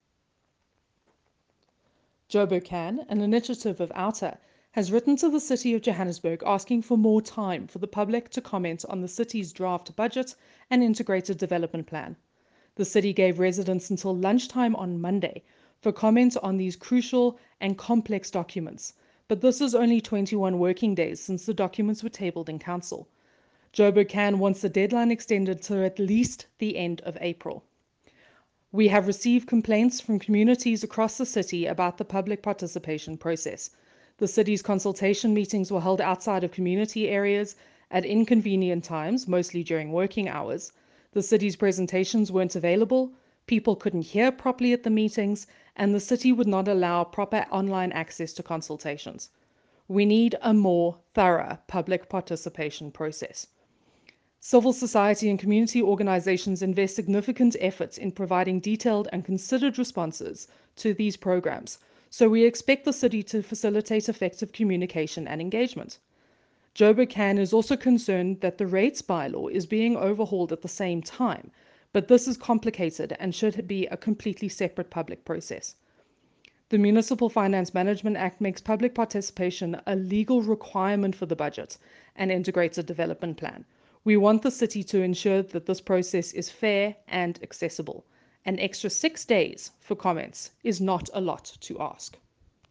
A soundclip with comment